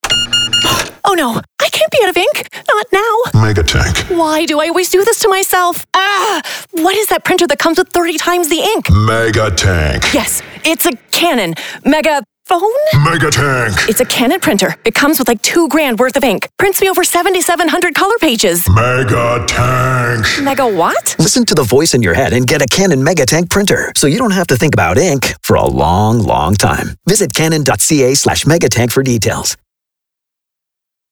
Authentic, warm and relatable.
Cannon MegaTank Commerical